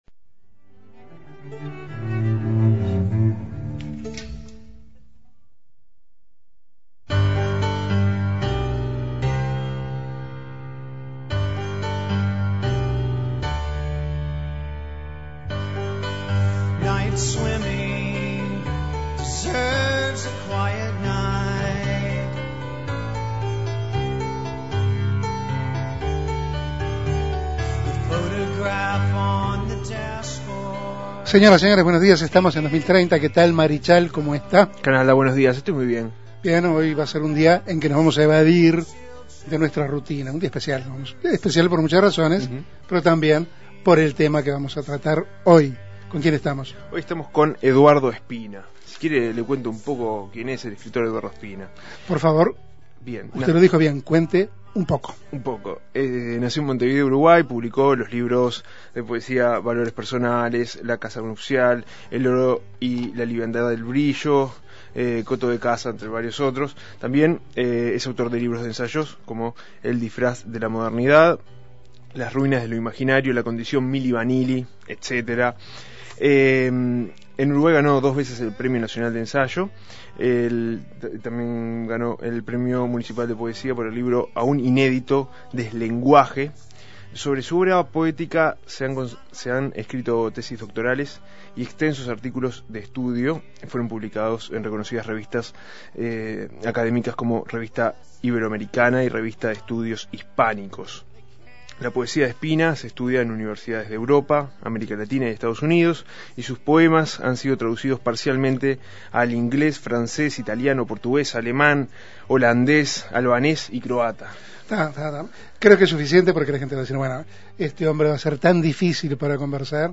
Invitado: Eduardo Espina.